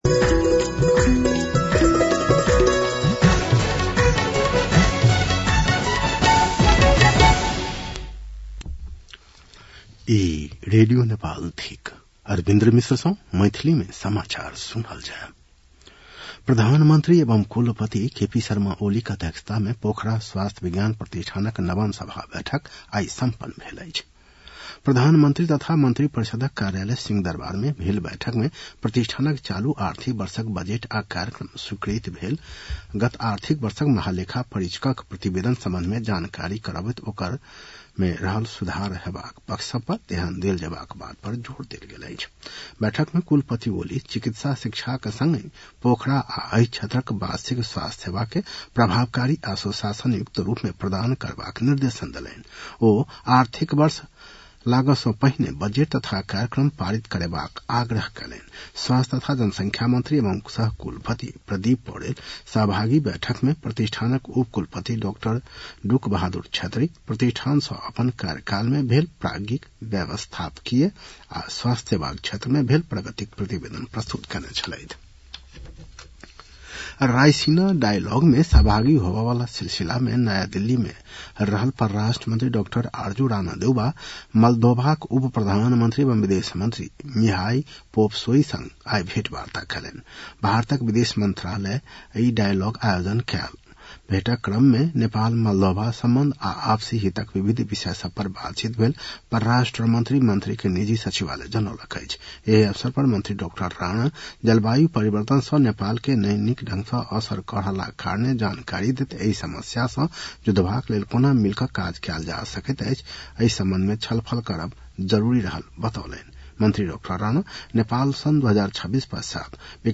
मैथिली भाषामा समाचार : ५ चैत , २०८१